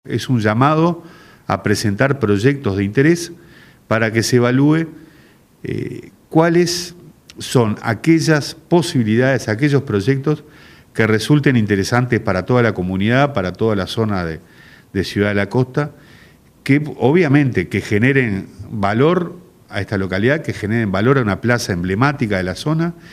Durante una conferencia de prensa en la sala de actos del municipio, la Alcaldesa, Mtra. Sonia Misirián, resaltó la importancia de este proyecto, que busca revitalizar este icónico espacio y promover la interacción entre diferentes generaciones, con un enfoque cultural y recreativo para la comunidad.